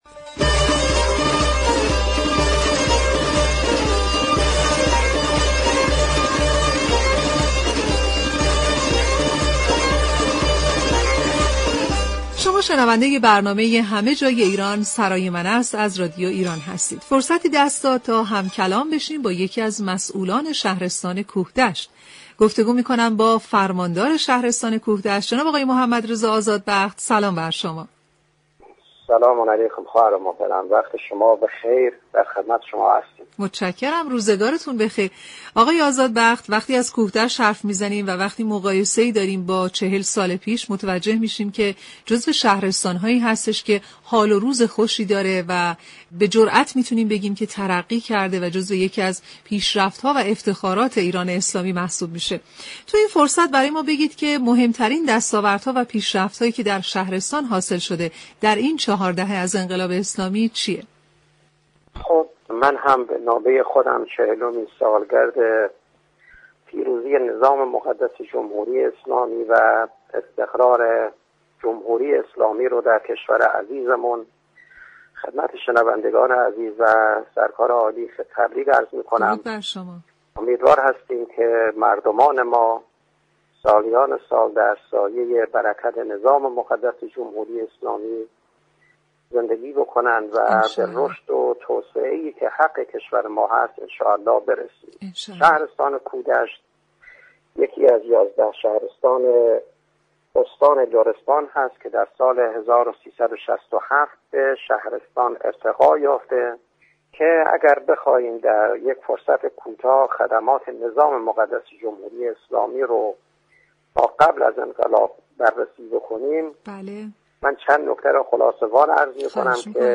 به گزارش شبكه رادیویی ایران؛ محمدرضا آزادبخت فرماندار شهرستان كوهدشت در برنامه همه جای ایران سرای من است از پیشرفت های كوهدشت طی 40 سال اخیر صحبت كرد و گفت : قبل از انقلاب راه آسفالت در كوهدشت نداشتیم اما بعد از انقلاب 80 كیلومتر راه روستایی آسفالته داریم.